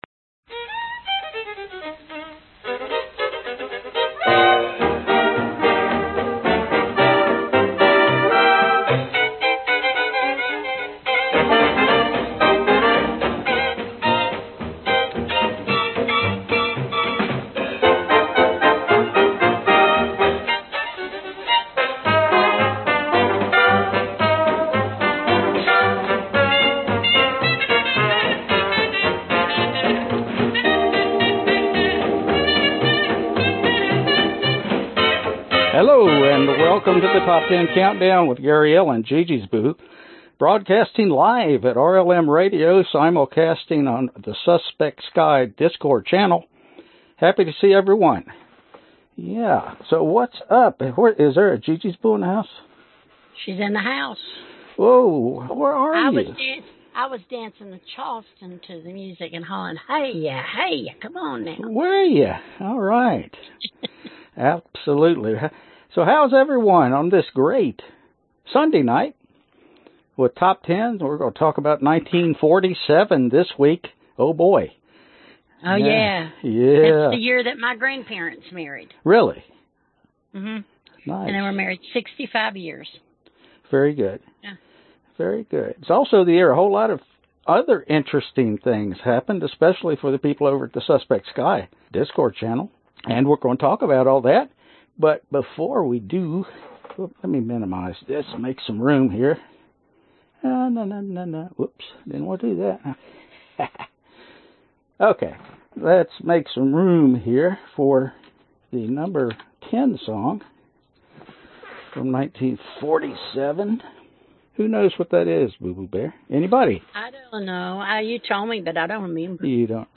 Top Ten Countdown - 1947 Genre Oldies Year 2021 Duration 59:26